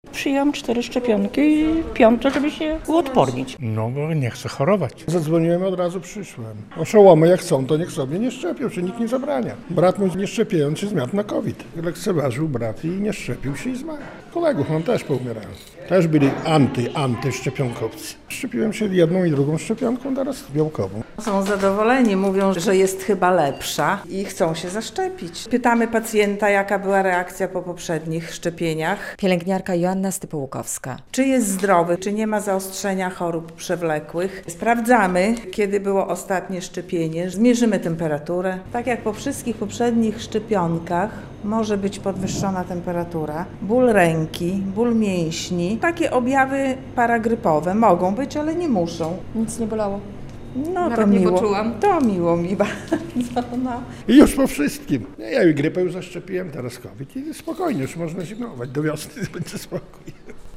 Szczepienia na COVID-19 - relacja